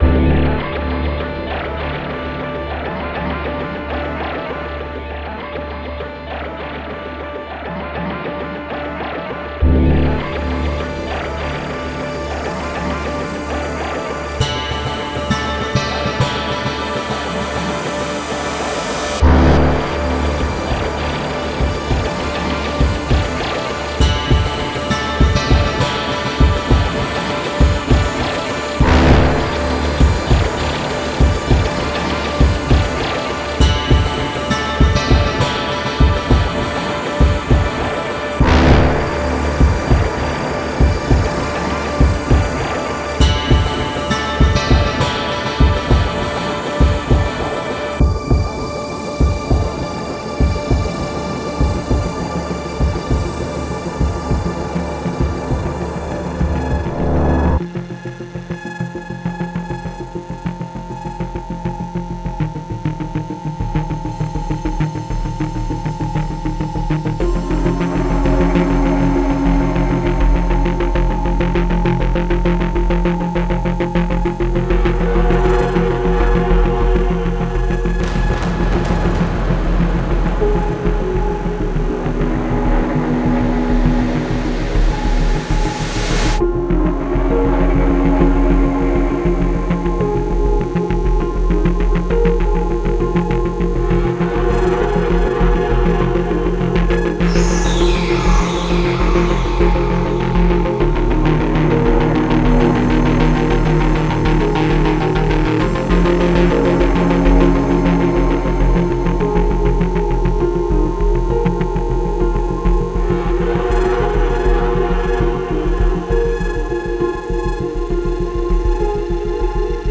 Genre:Action
すべてのビートが衝撃の瞬間の直前であるかのように感じられる世界です。
デモサウンドはコチラ↓
80 Loops:
13 Bass Loops
18 Drone Loops
16 Pad Loops
11 Piano Loops
05 String Loops